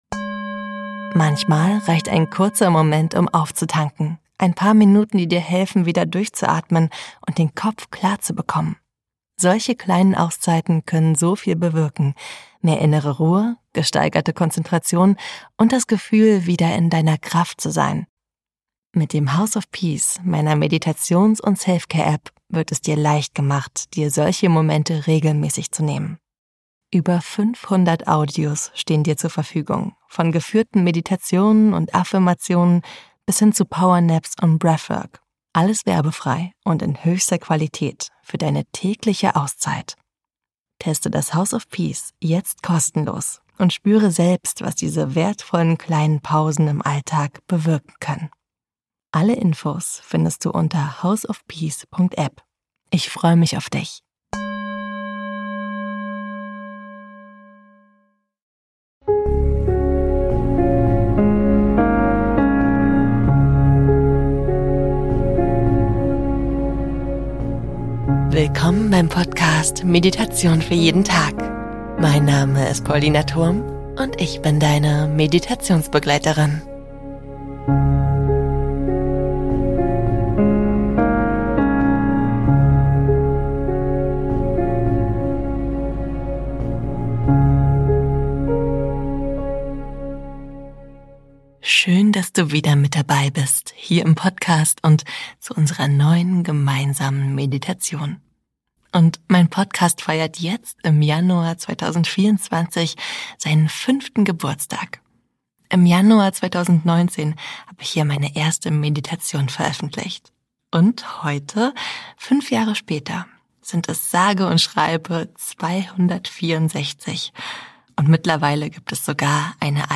➡ House of Peace - jetzt ausprobieren!Genieße alle meine über 250 geführten Meditationen - ohne Intro, Outro oder Werbung.